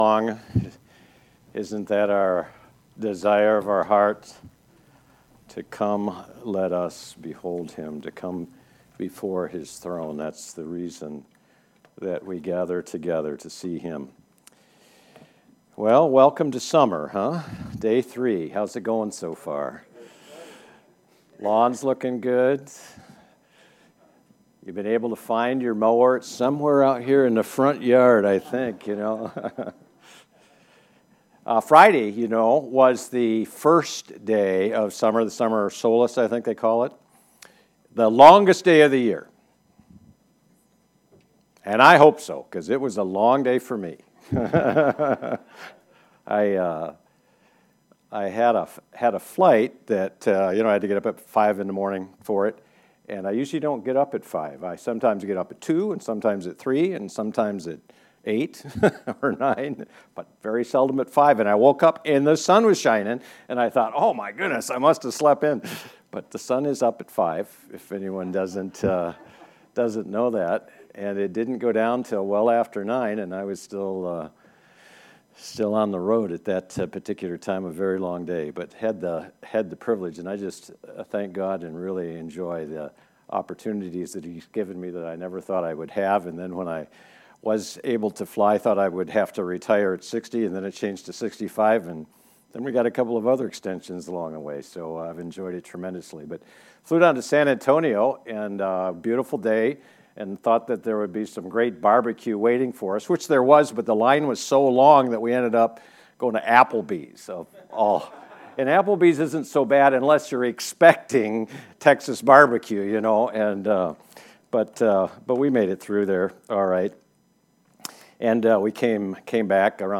Colossians 2 Service Type: Sunday Morning Bible Text
Sermon-6-23-Colossians-2.mp3